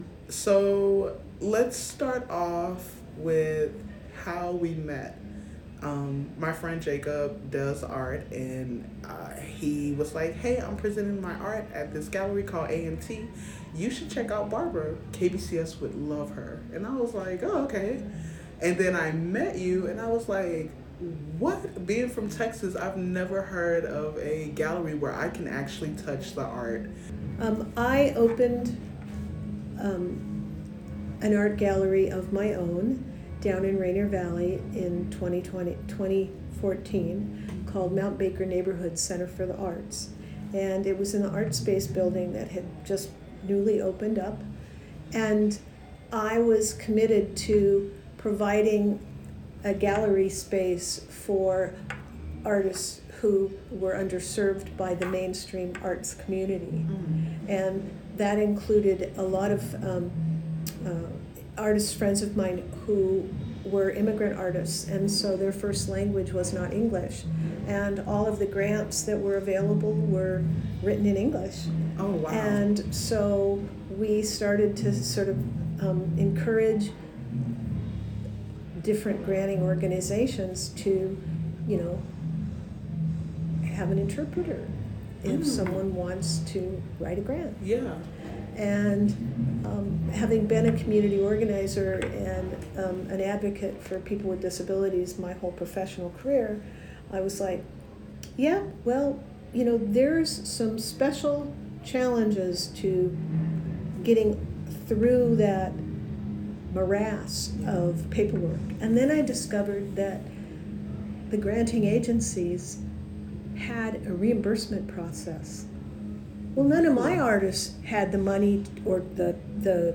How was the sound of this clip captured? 91.3 KBCS Revision Arts Exhibit Coverage: A Queen Exhibit at King St. Station